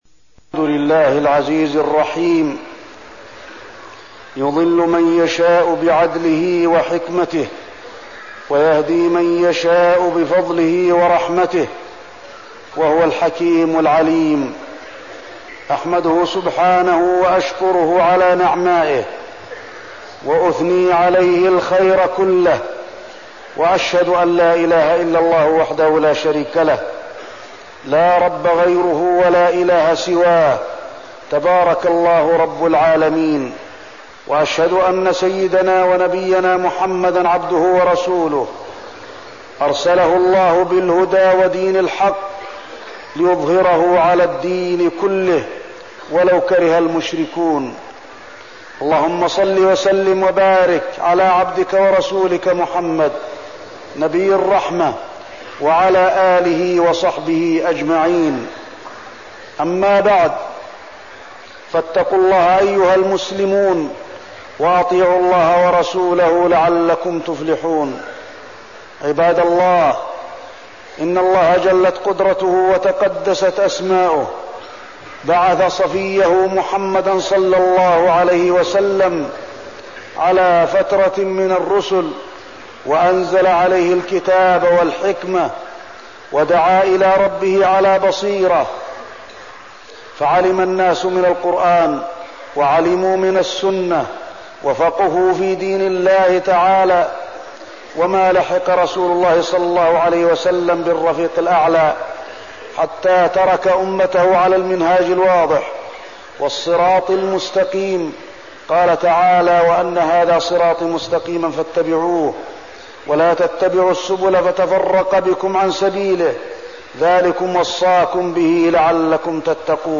تاريخ النشر ٢٤ ذو الحجة ١٤١٤ هـ المكان: المسجد النبوي الشيخ: فضيلة الشيخ د. علي بن عبدالرحمن الحذيفي فضيلة الشيخ د. علي بن عبدالرحمن الحذيفي الفرقة الناجية وصفاتها